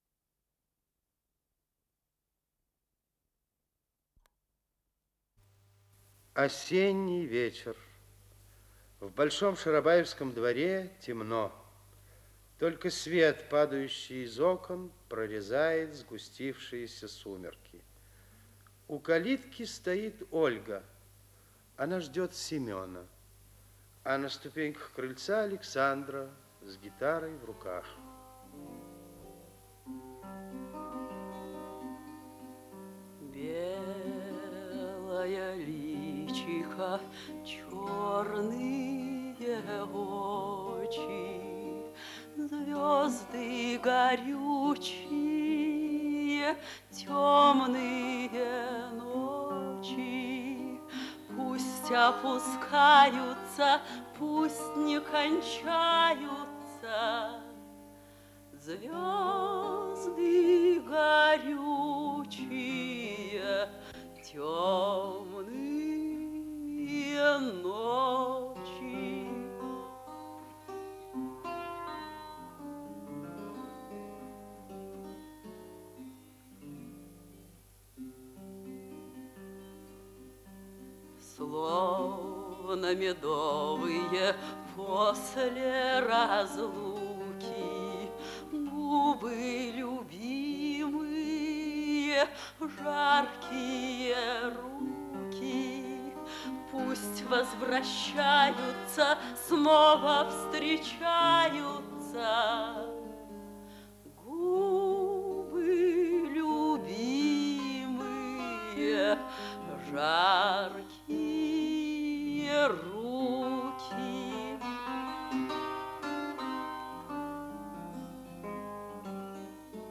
Исполнитель: Артисты Малого театра
Спектакль ГАМТ